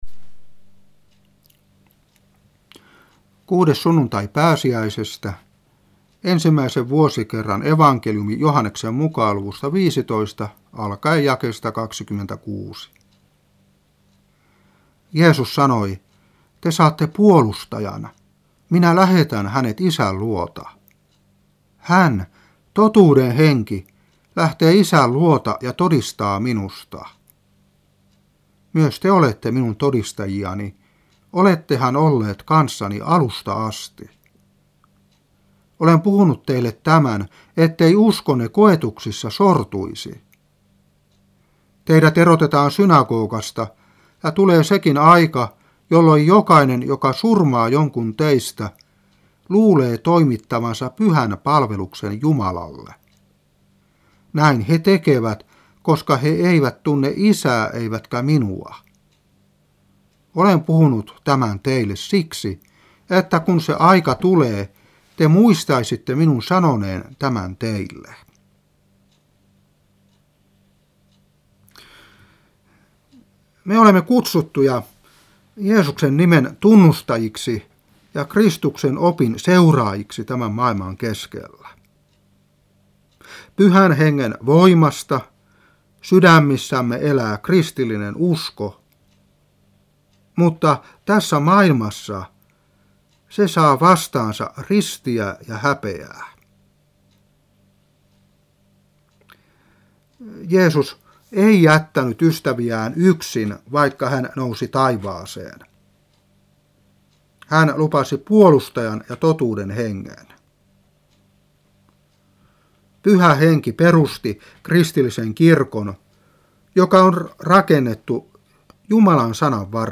Saarna 1993-5. Joh.15:26-16:4.